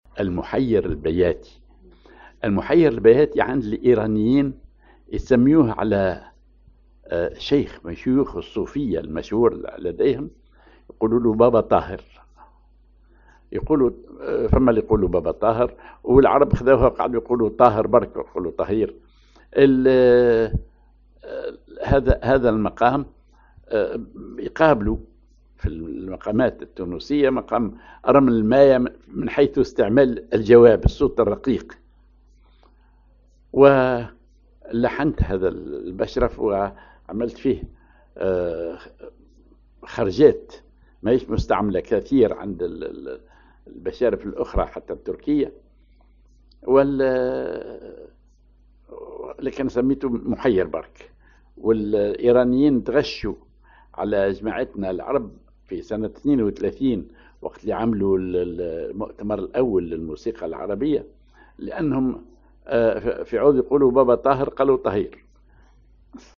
Maqam ar محير بياتي
Rhythm ar مخمس
genre بشرف